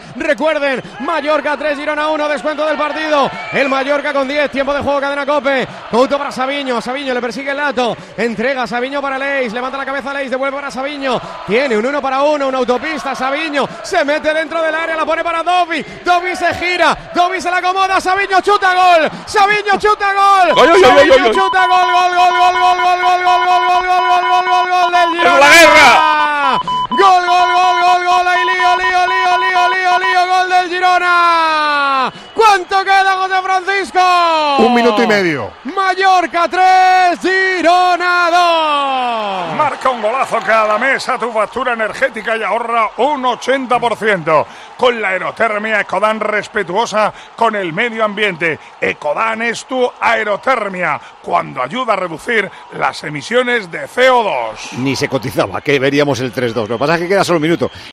Así vivimos en Tiempo de Juego la retransmisión del Mallorca - Girona